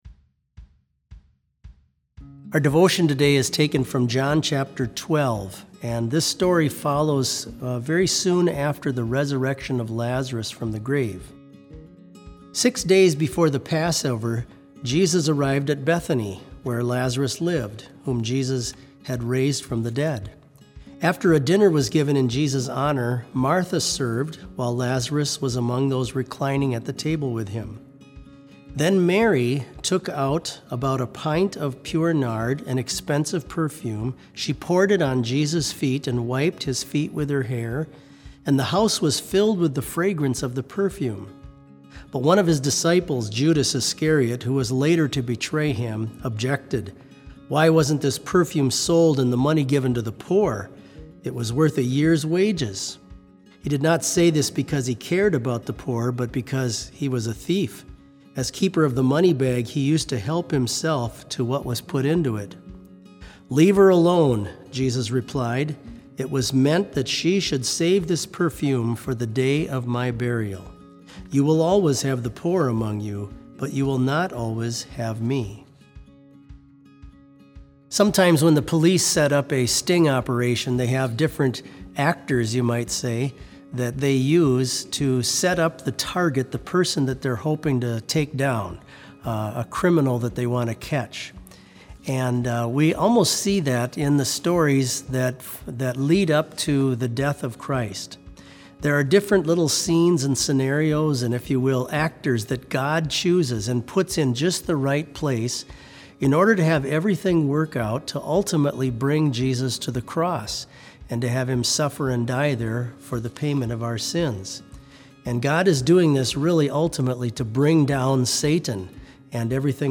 Complete service audio for BLC Devotion - April 6, 2020